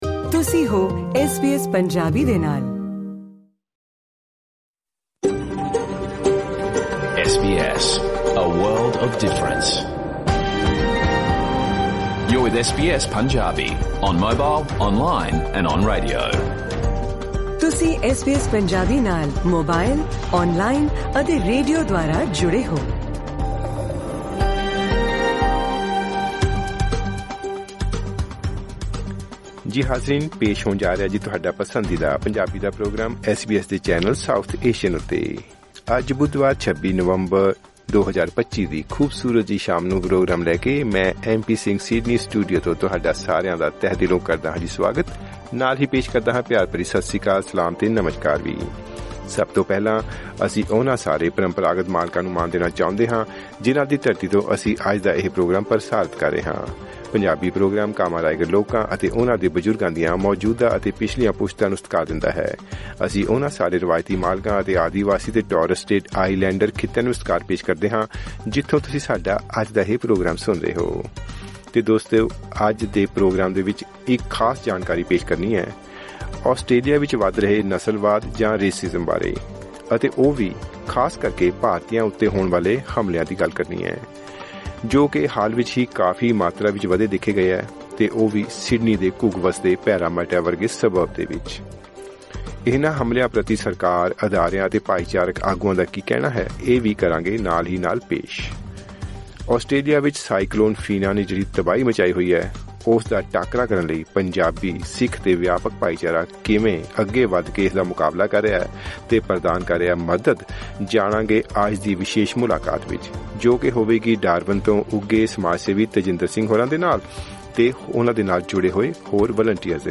Listen to the SBS Punjabi full radio program
This SBS Punjabi radio program brings a wide range of informative segments, featuring key news from Australia and around the world. It also covers the rise in attacks against Indians living in Australia. Do not miss a special report on how the Punjabi/Sikh community helped the Northern Territory residents after the destruction caused by Cyclone Fina. The program also includes a round-up of the latest Bollywood updates in the weekly section 'Bollywood Gup Shup'.